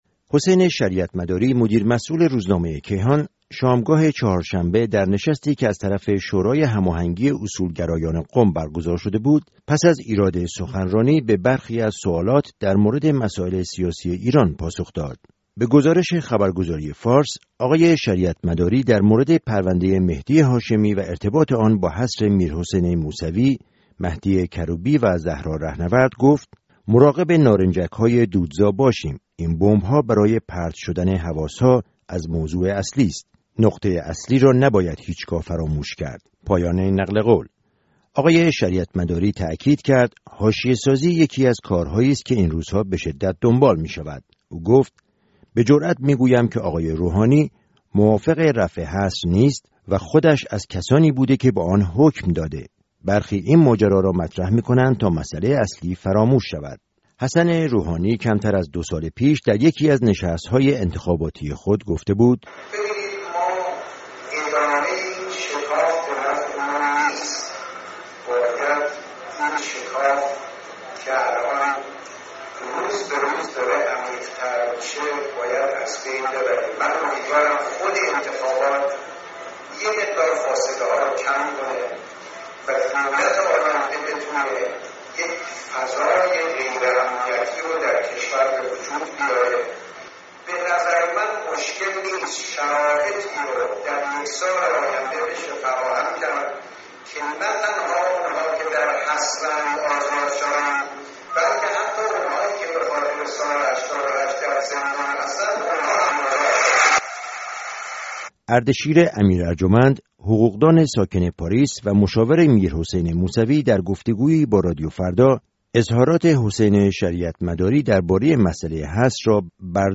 گزارش تفصیلی